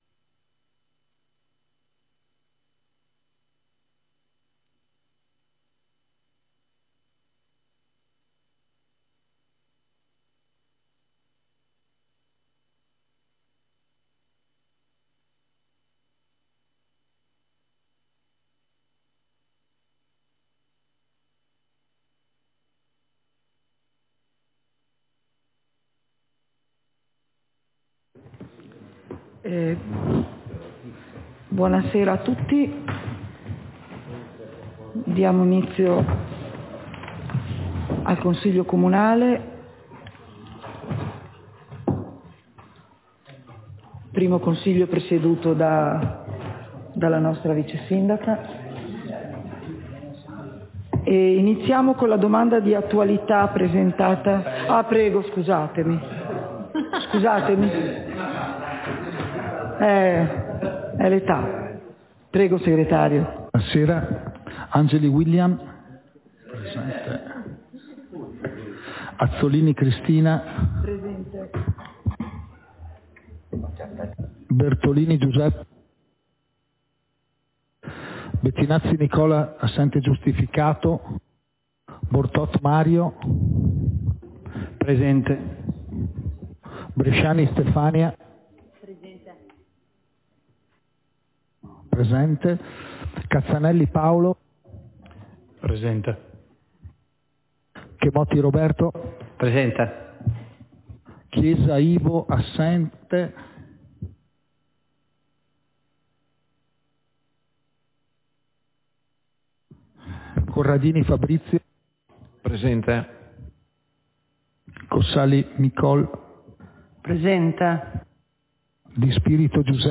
Seduta del consiglio comunale - 26.09.2023